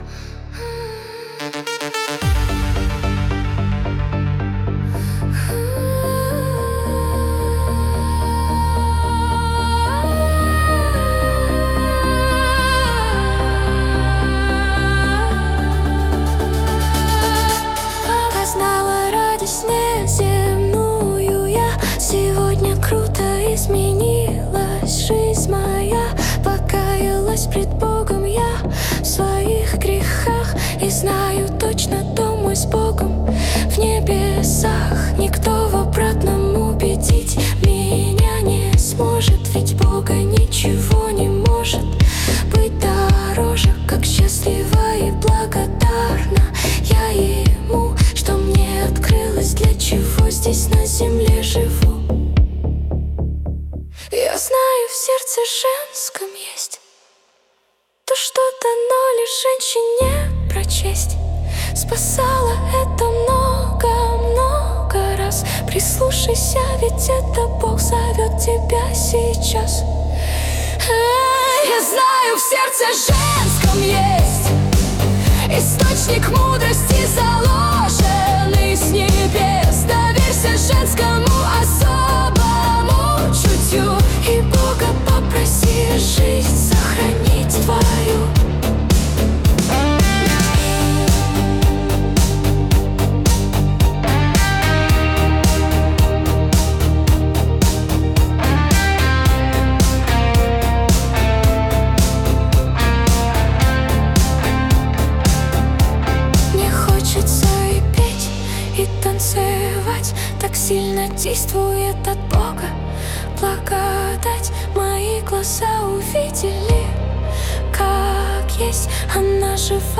песня ai
141 просмотр 502 прослушивания 22 скачивания BPM: 109